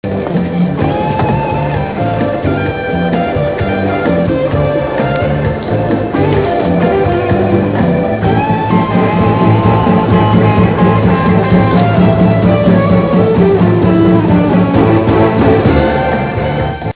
Comment: rock